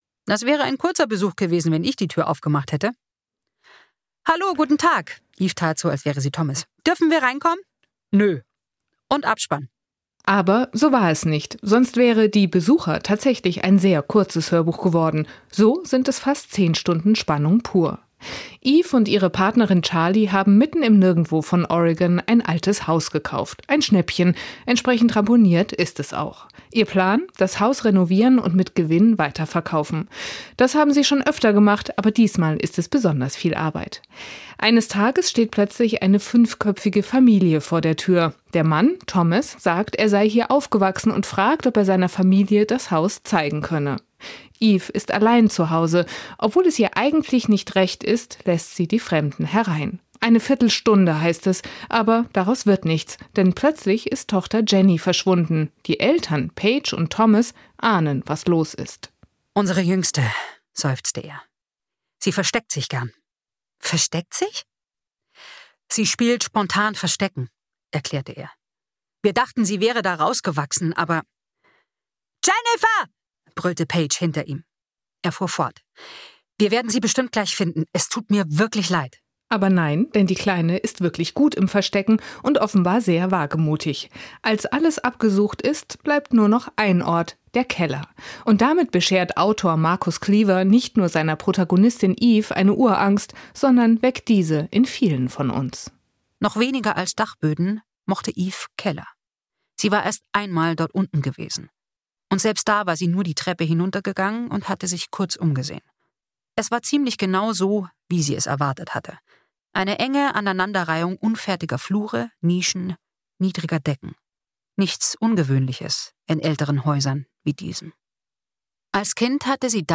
Hörbuch
Schnell wird klar: Dieses Haus hat eine lange mysteriöse Geschichte und entwickelt bald ein Eigenleben. Das Verwirrspiel um Identitäten und erschreckende Begegnungen wird von dem Ensemble souverän umgesetzt – Hochspannung garantiert.
hoerbuch-die-besucher-von-marcus-kliewer.mp3